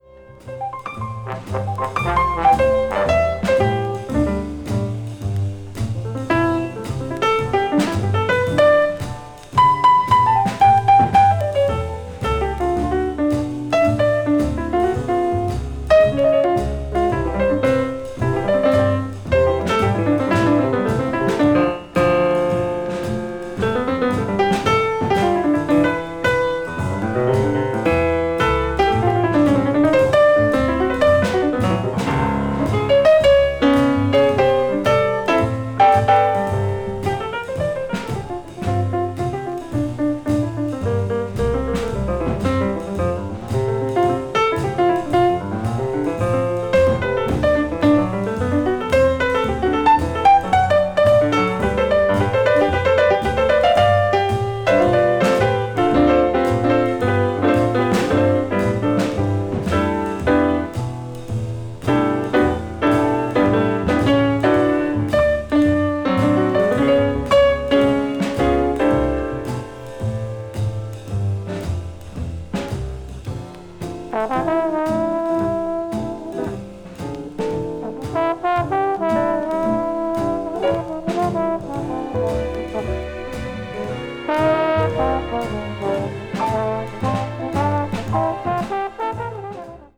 VG+/VG+(薄く細かいスリキズによるわずかなチリノイズ/軽いプチノイズが入る箇所あり)
全編素晴らしいですが、バラード・チューンがグッと沁みます。